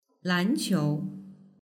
lán qiú